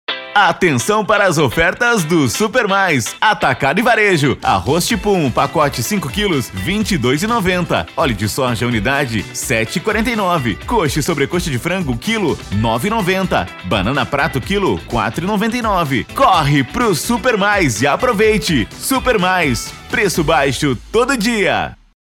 Varejo mercado: